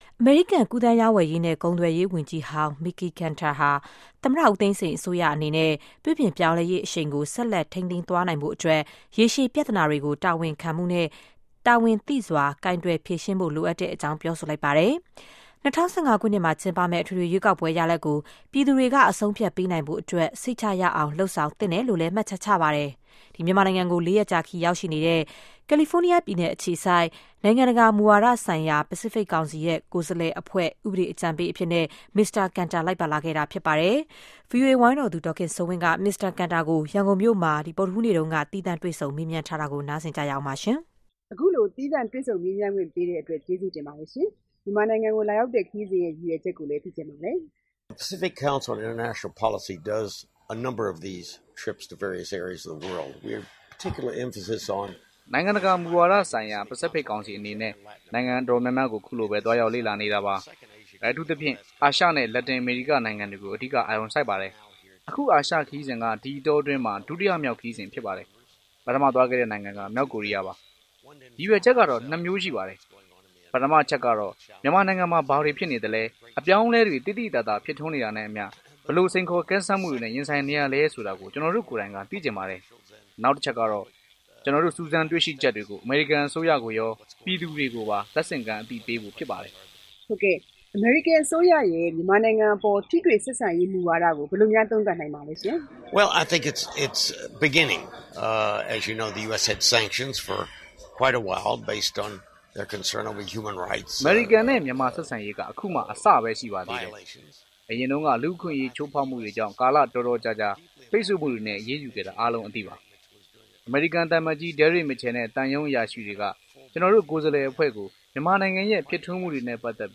IV with US Trade Team